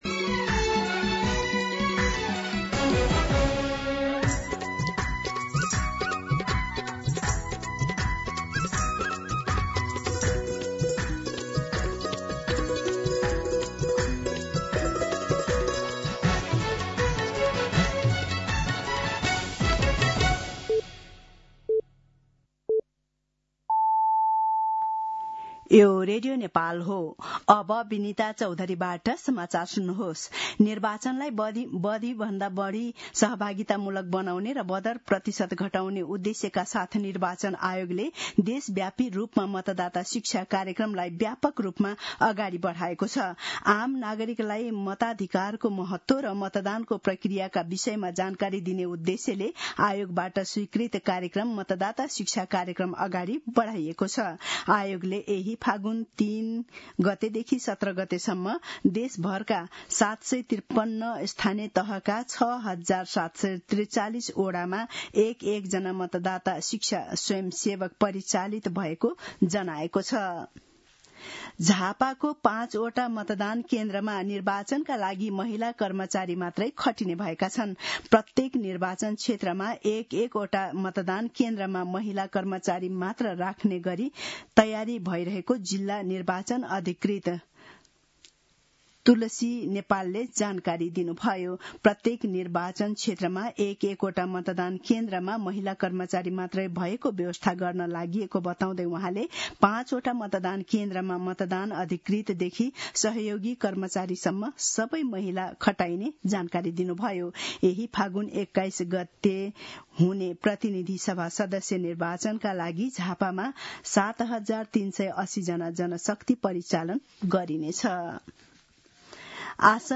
मध्यान्ह १२ बजेको नेपाली समाचार : १० फागुन , २०८२
12-pm-Nepali-News-5.mp3